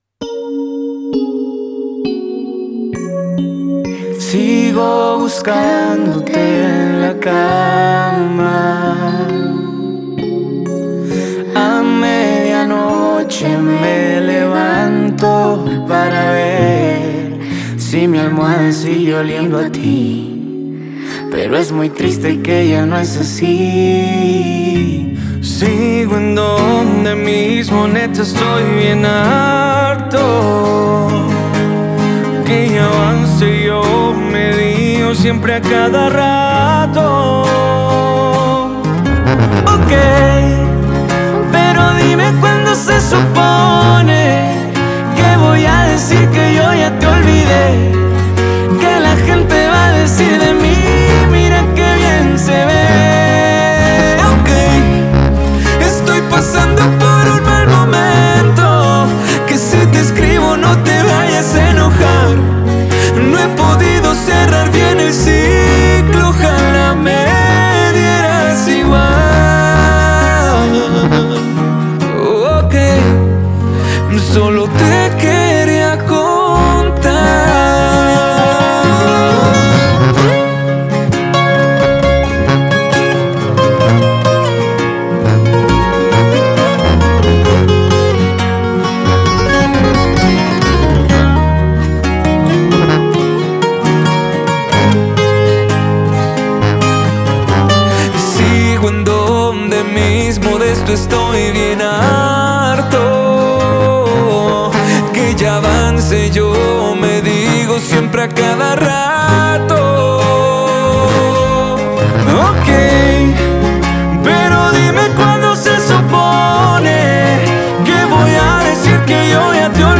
una conmovedora colaboración